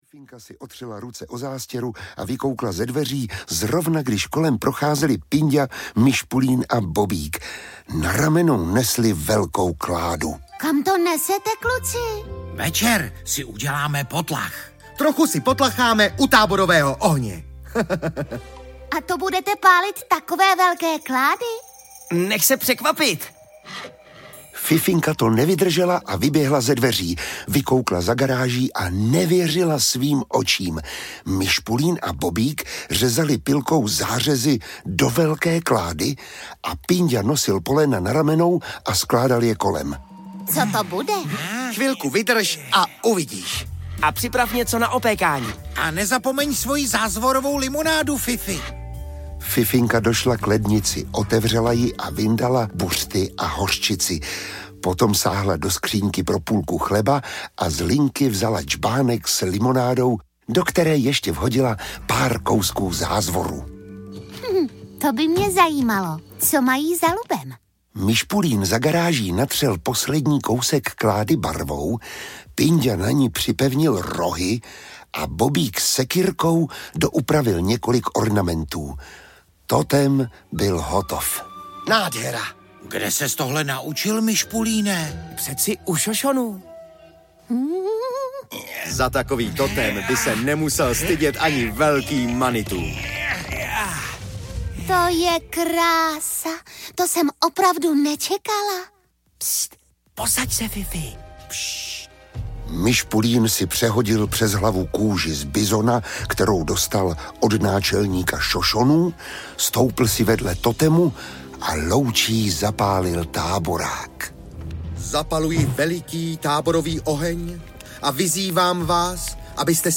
Velké dobrodružství Čtyřlístku audiokniha
Ukázka z knihy